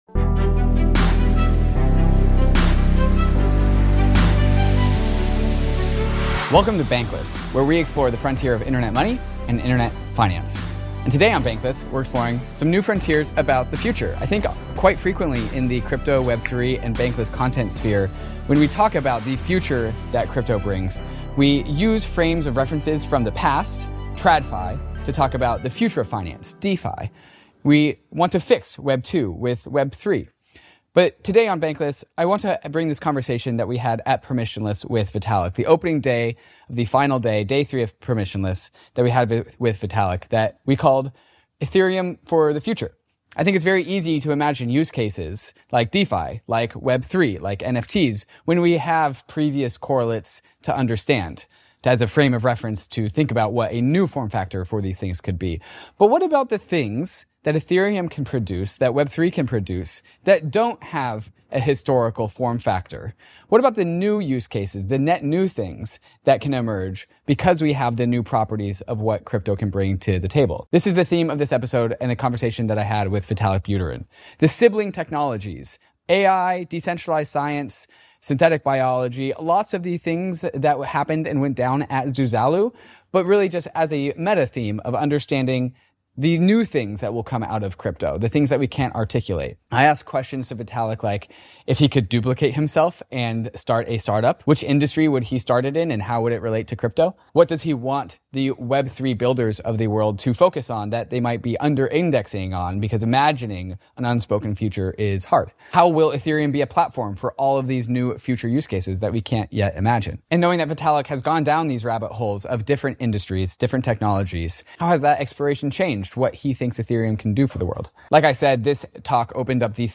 Vitalik live from Permissionless!
This episode was filmed at permissionless.